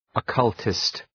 Προφορά
{ə’kʌltıst}